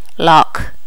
Additional sounds, some clean up but still need to do click removal on the majority.
lock.wav